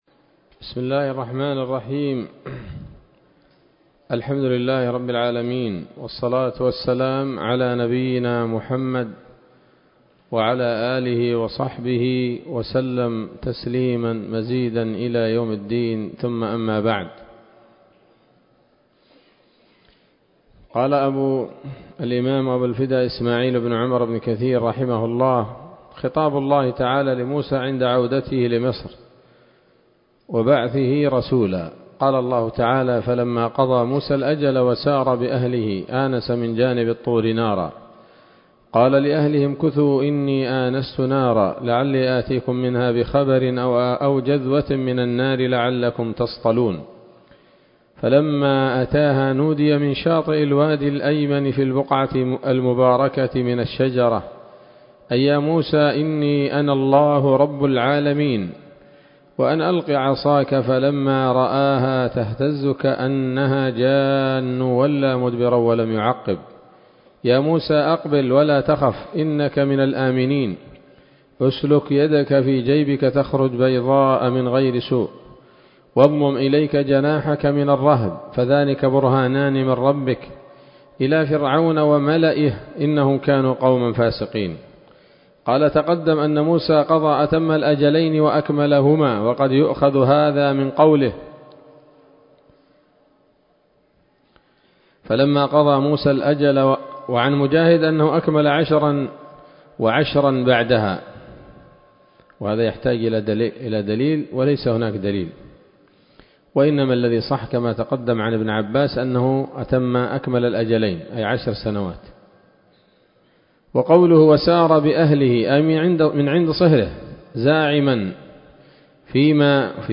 الدرس الخامس والثمانون من قصص الأنبياء لابن كثير رحمه الله تعالى